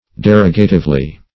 -- De*rog"a*tive*ly , adv.